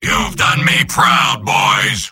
Robot-filtered lines from MvM. This is an audio clip from the game Team Fortress 2 .
Soldier_mvm_cheers02.mp3